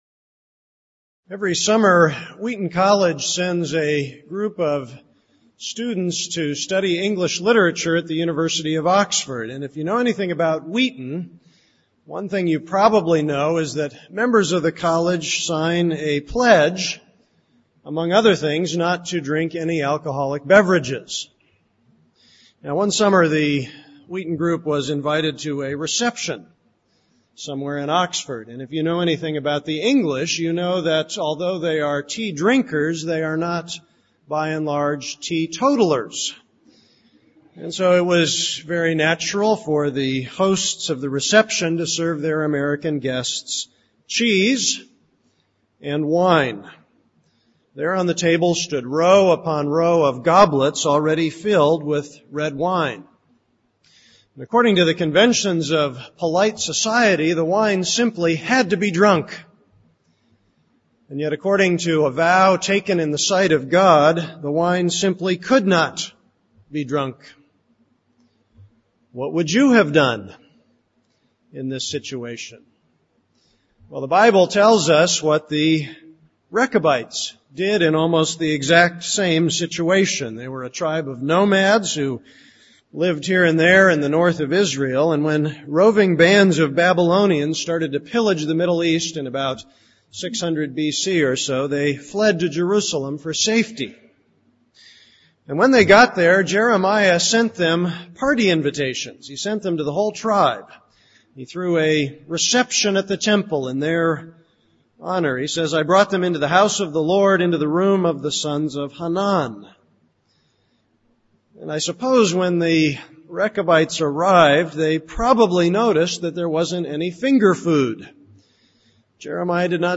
This is a sermon on Jeremiah 35:1-19.